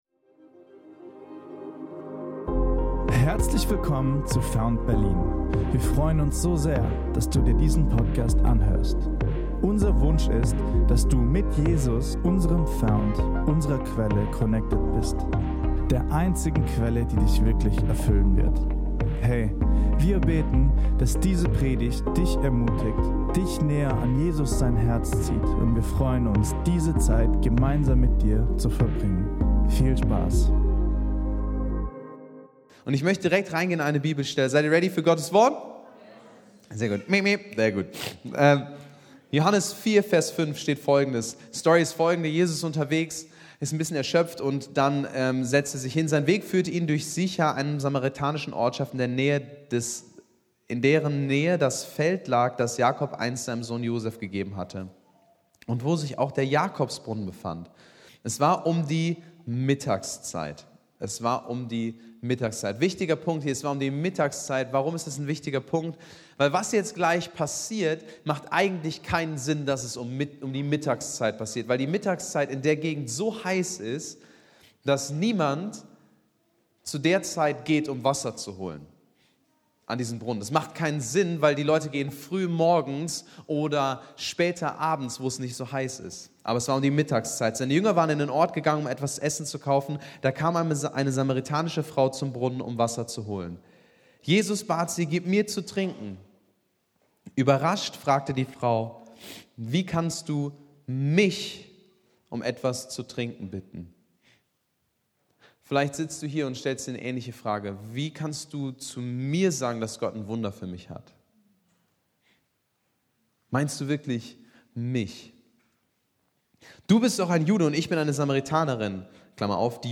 In der ersten Predigt als FOUNT Berlin entdecken wir, wie wir ein wahres Leben in der Fülle von Jesus erleben können. Jesus will dich für immer in seiner Nähe haben.